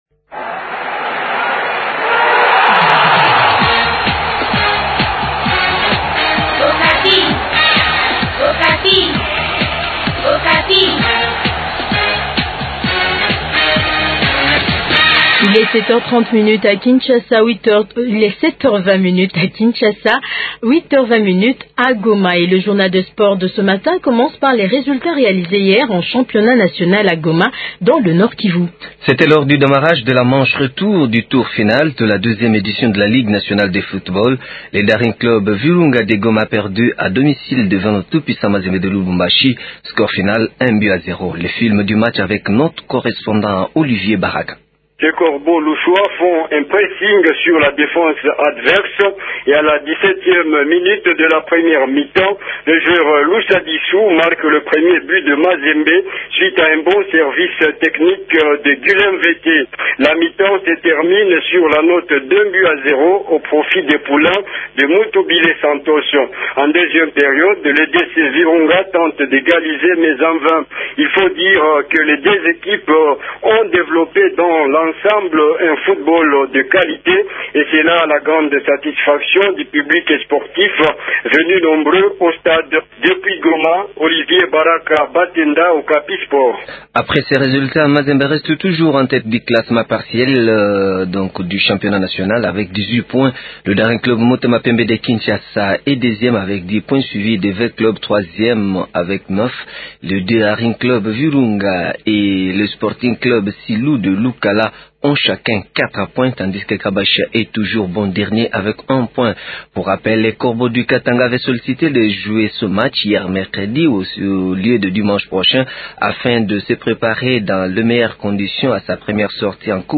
Jounal Sports Matin